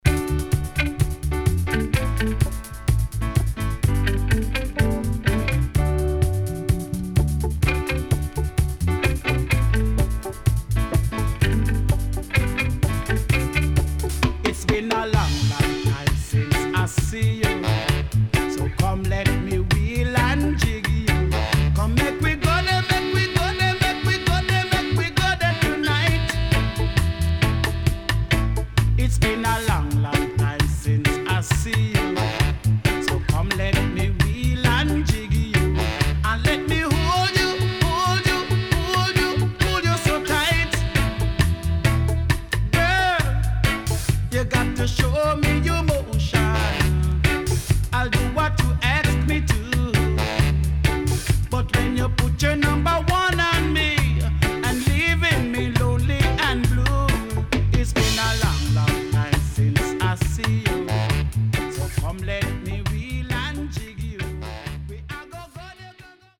渋Vocal 後半Deejay接続.Good Condition
SIDE A:少しチリノイズ入りますが良好です。